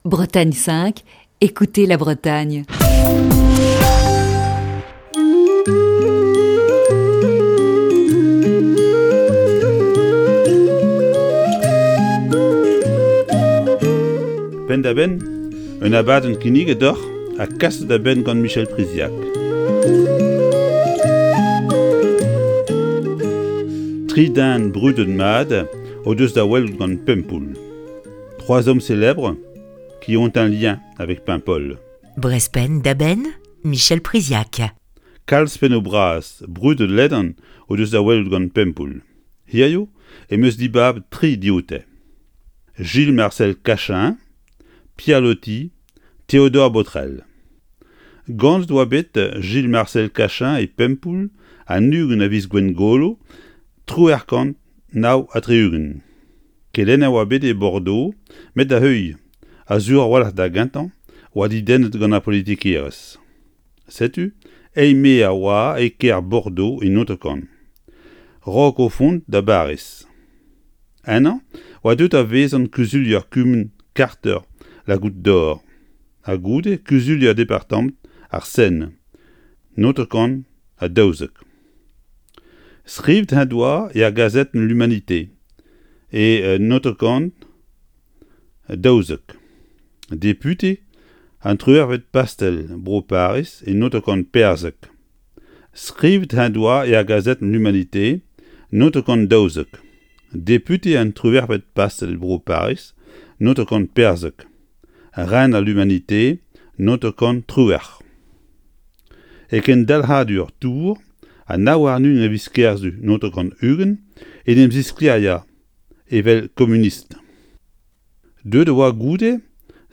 Chronique du 28 février 2020.